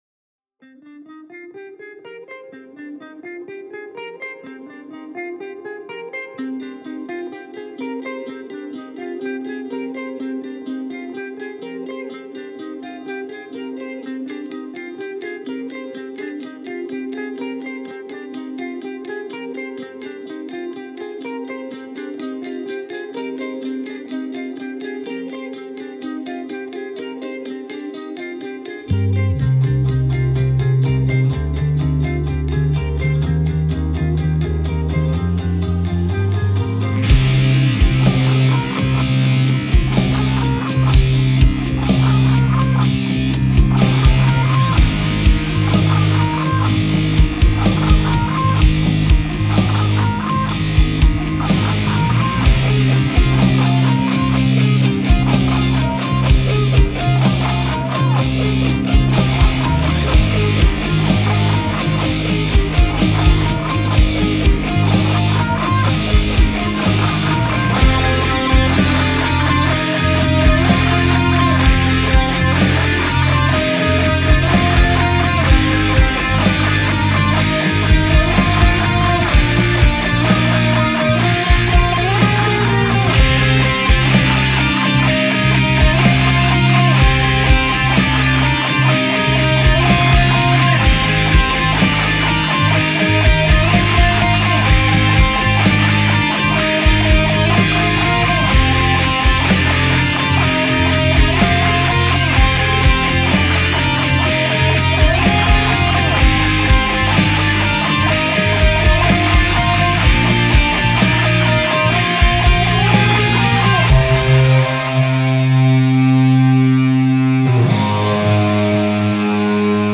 • genre post rock , Concept music